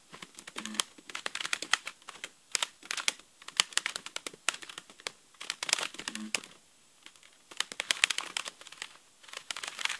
Звук бумаги
Листает страницы журнала: